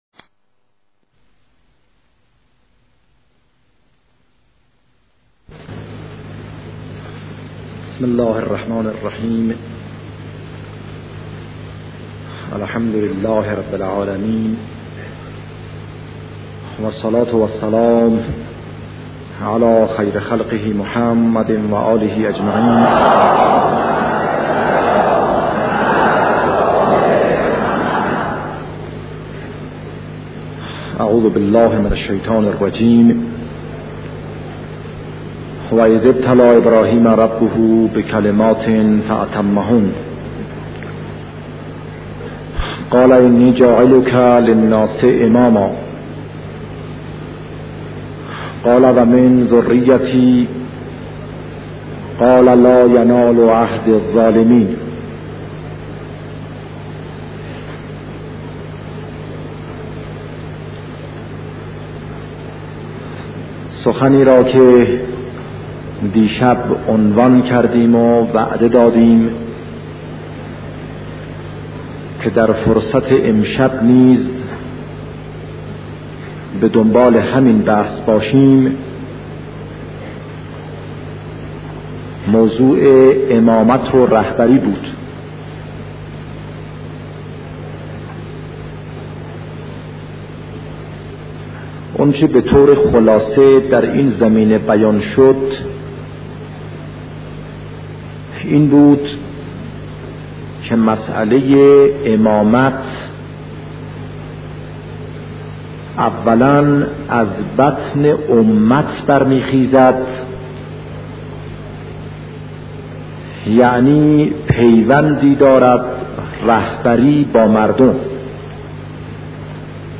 بررسی موضوع امامت و رهبری - صوت شهید باهنر (ره)